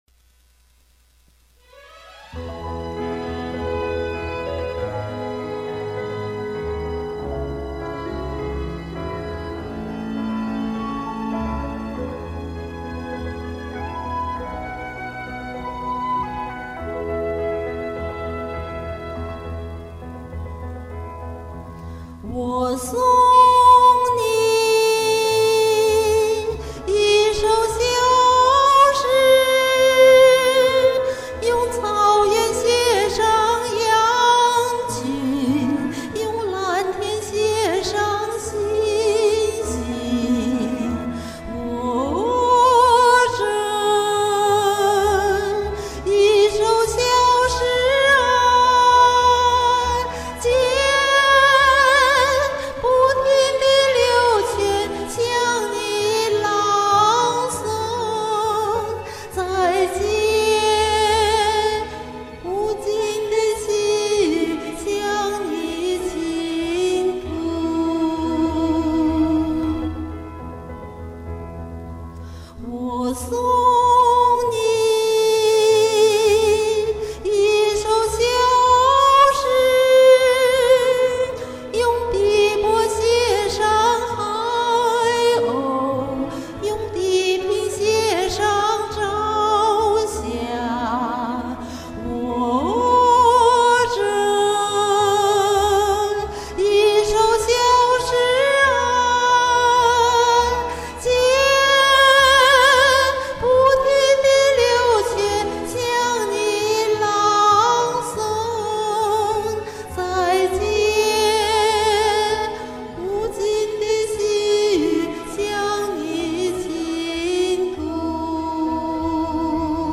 台湾校园校园歌曲
我曾在高山贴过这首歌的自重唱，这版是独唱。
一直找不到她演唱的这首歌的伴奏，可我就喜欢她演唱的版本，我只好做了消音伴奏，把她声音抹去。凑合着录了这一遍。